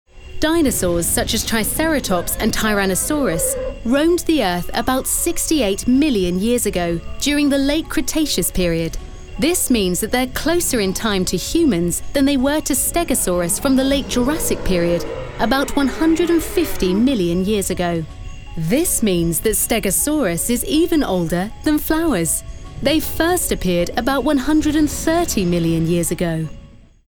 Inglés (Británico)
Comercial, Cool, Versátil, Cálida
Explicador
Audioguía